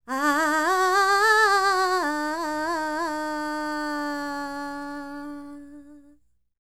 QAWALLI 02.wav